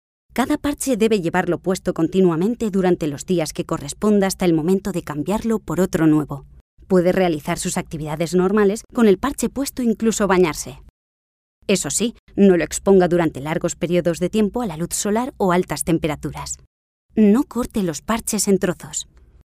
grabación de vídeo médico.